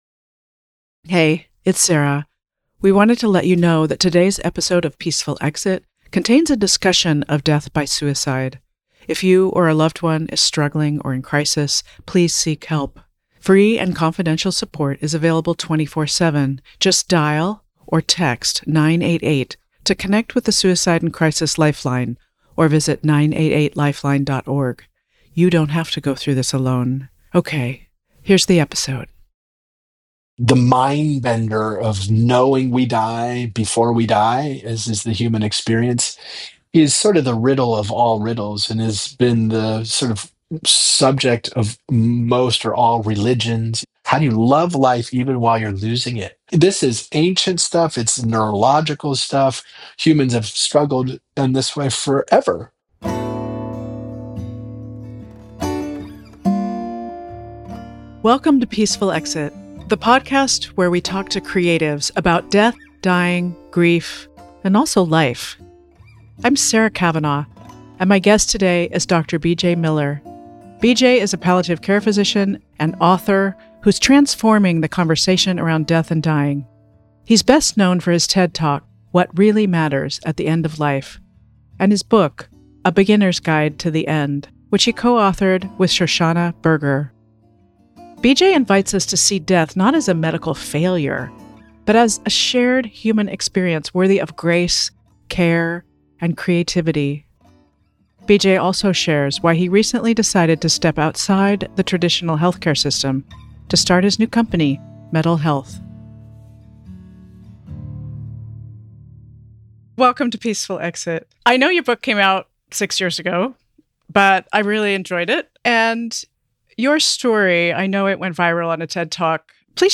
In this intimate conversation